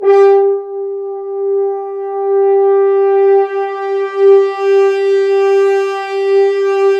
Index of /90_sSampleCDs/Roland L-CD702/VOL-2/BRS_F.Horns sfz/BRS_FHns sfz